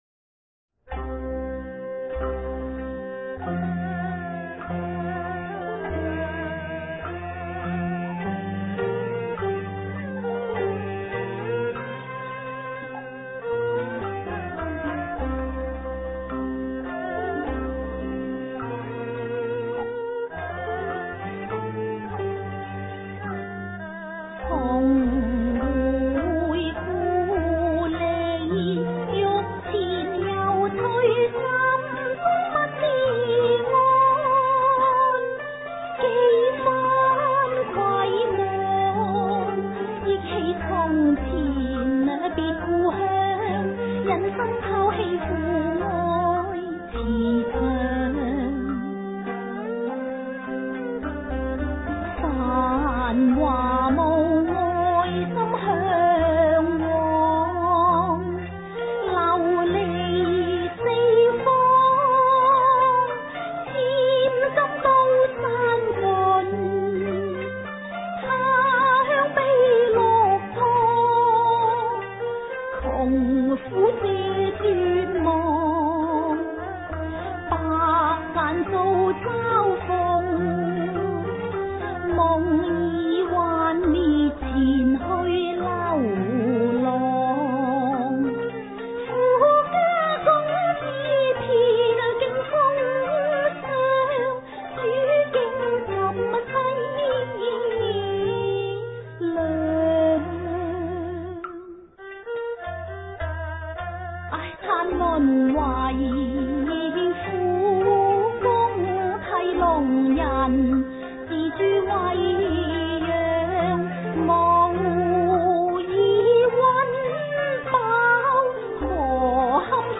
福音粵曲